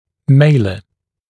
[ˈmeɪlə][ˈмэйлэ]скуловая кость; скуловой